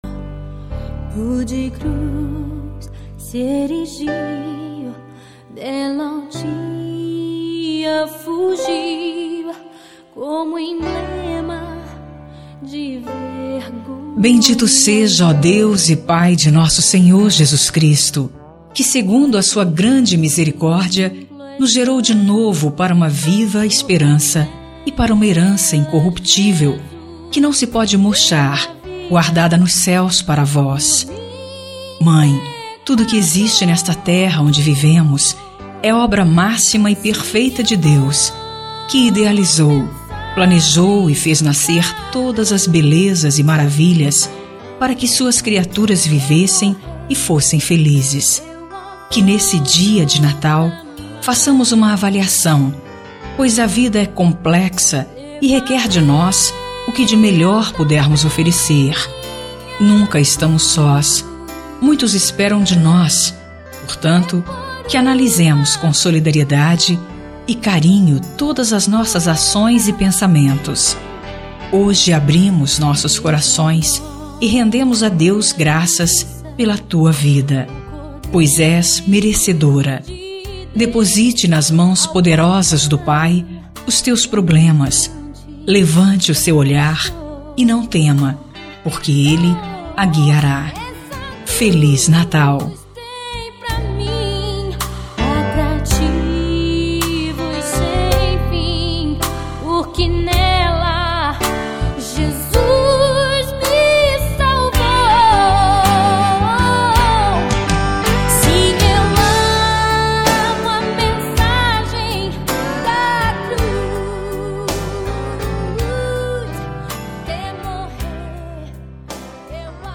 Telemensagem de Natal – Voz Feminina – Cód : 240107 – Mãe